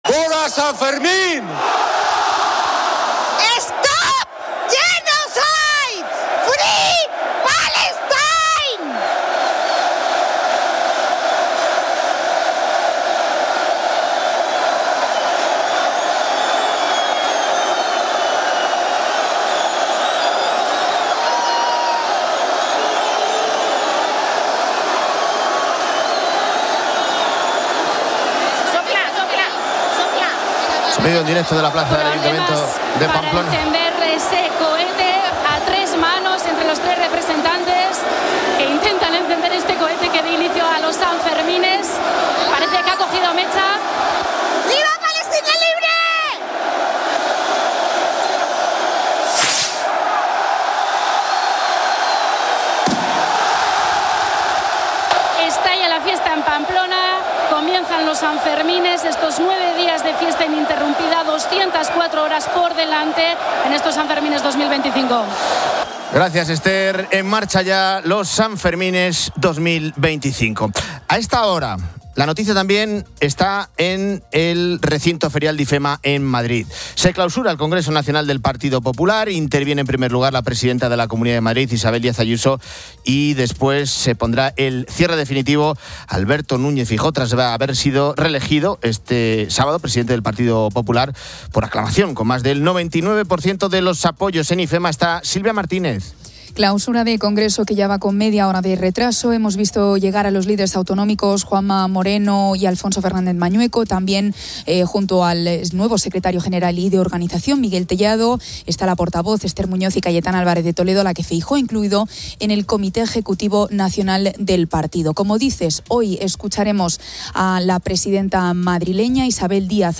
En la tertulia hablamos con los oyentes de ese viaje inolvidable en coche.